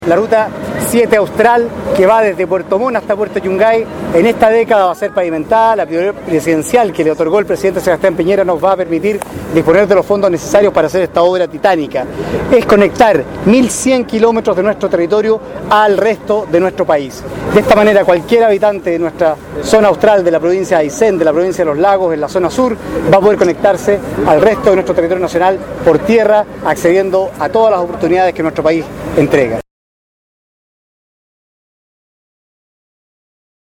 Escuche al Ministro Laurence Golborne aquí.